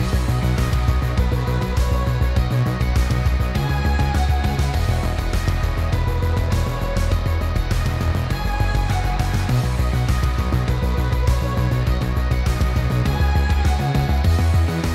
Was ist das für ein Effekt? -> Soundbeispiel